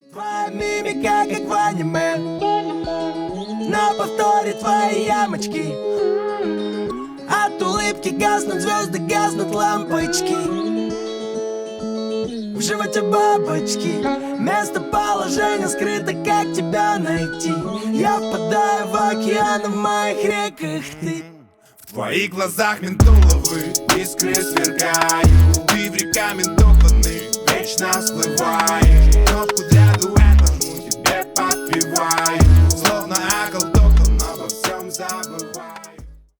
Поп Музыка
милые